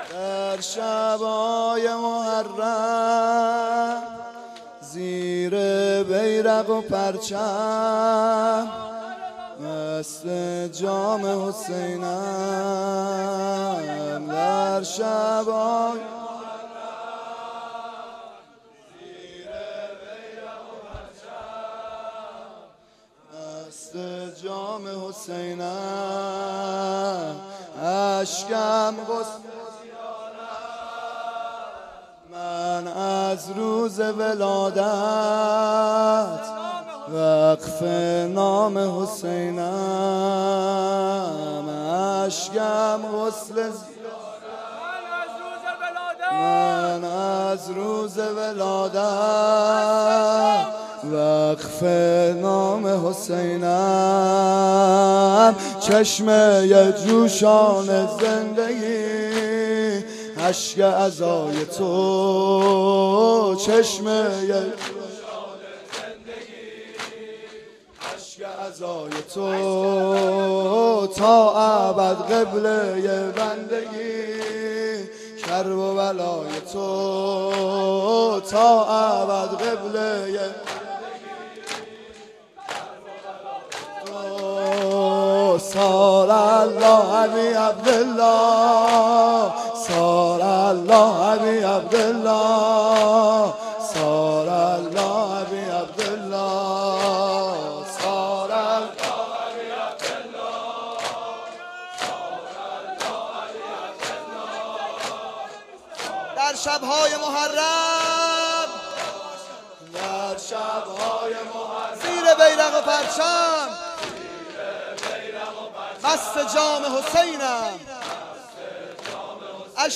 04-shabe10-nohe.mp3